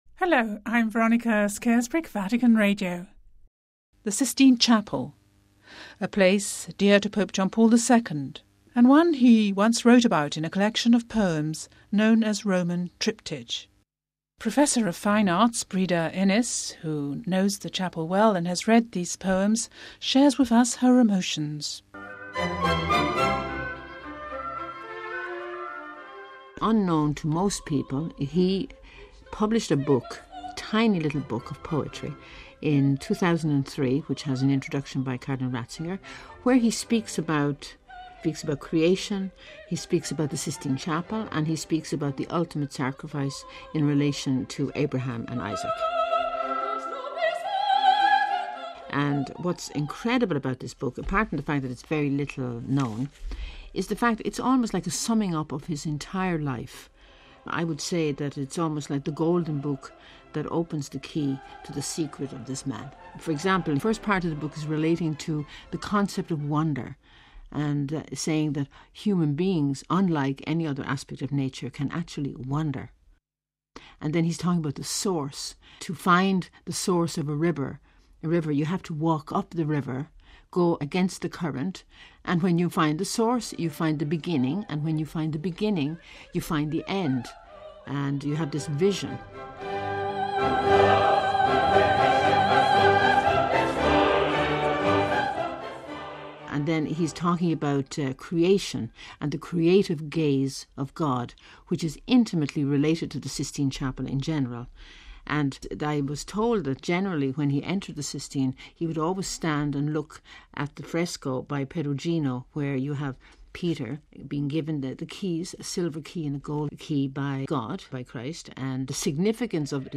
(Vatican Radio) On the eve of the Feast of All Saints Benedict XVI presides over Vespers in the Sistine Chapel.